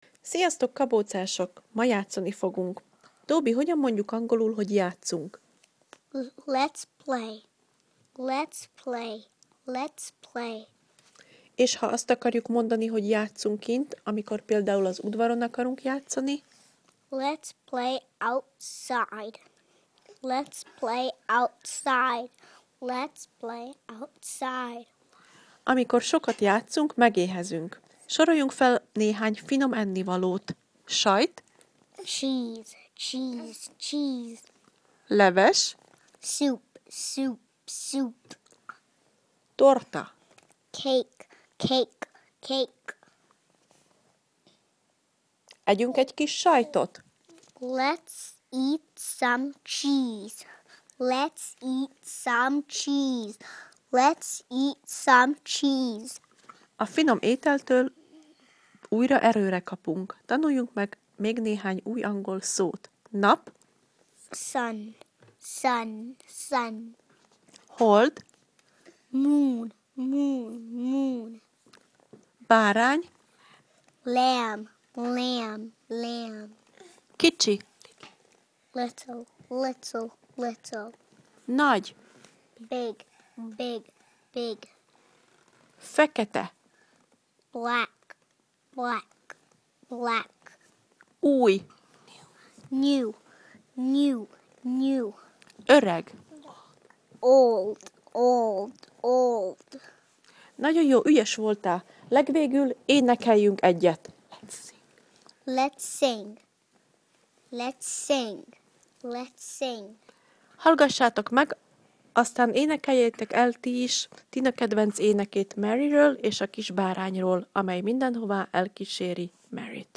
TobyAz ebben a leckében szereplő szavak helyes kiejtését meghallgathatod Tobytól